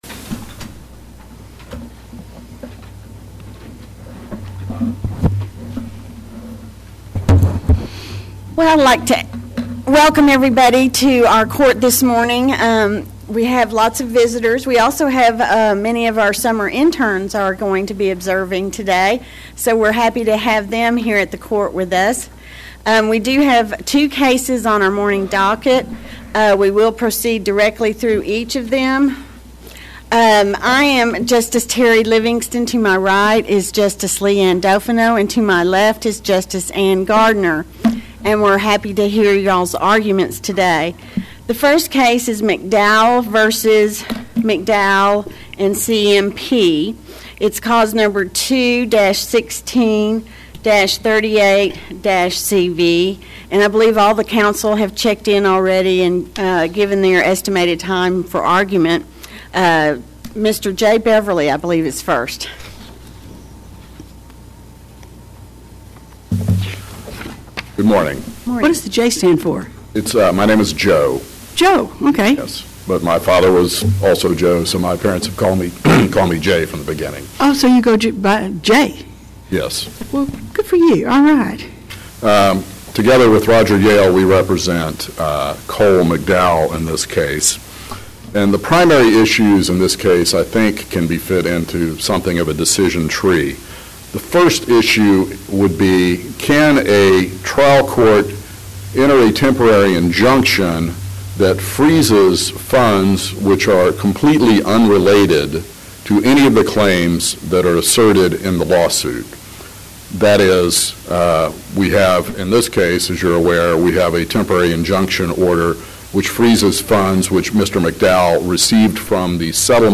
TJB | 2nd COA | Practice Before the Court | Oral Arguments | 2016